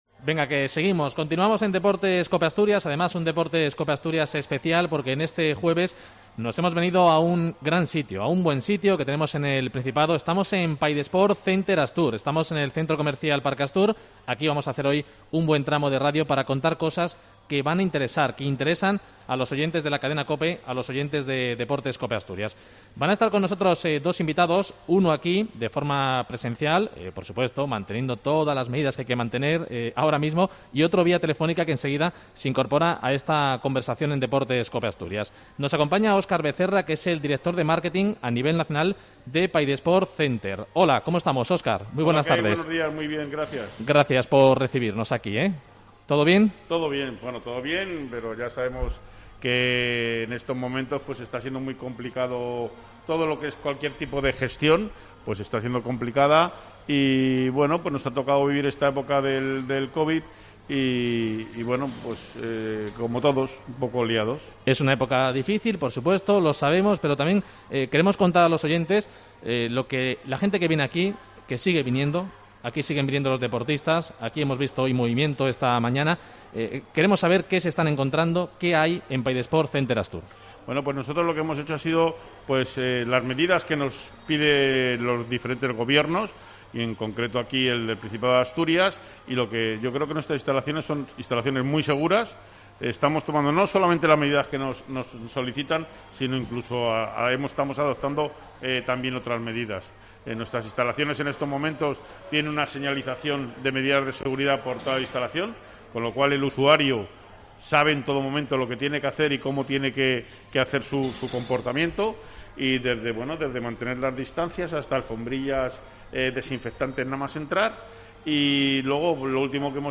Programa especial en Paidesport Center Astur